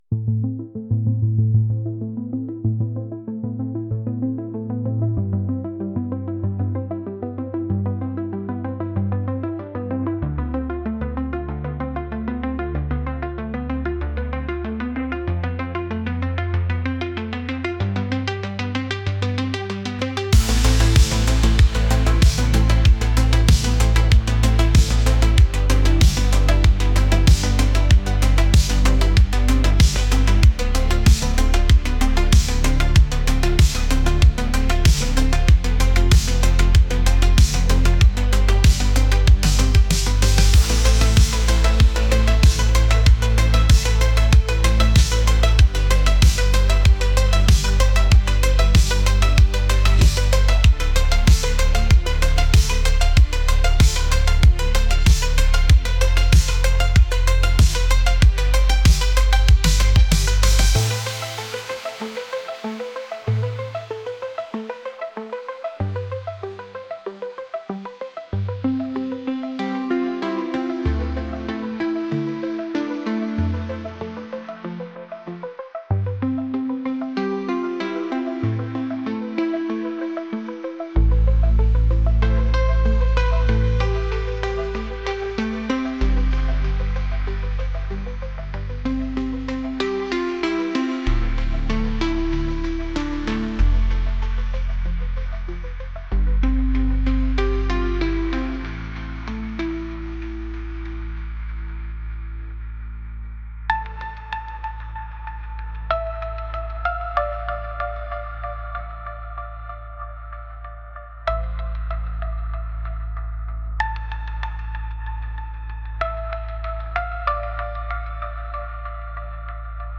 electronic | dreamy